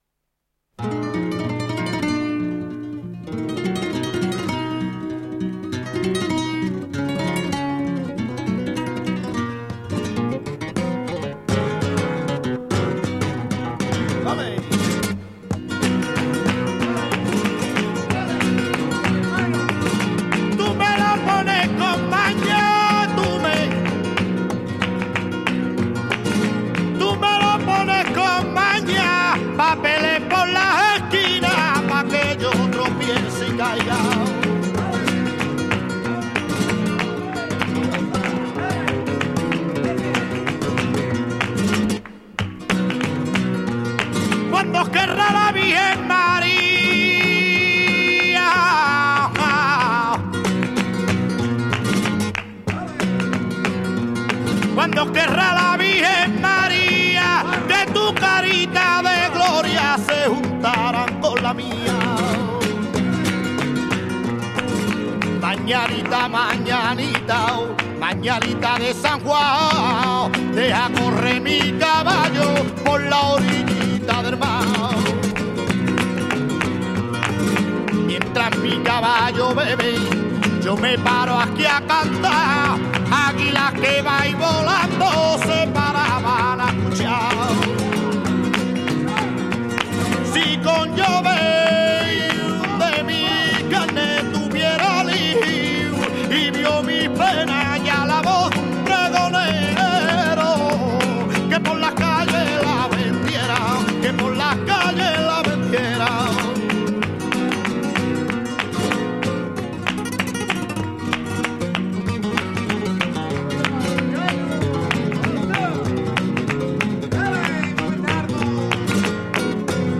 Tangos
guitare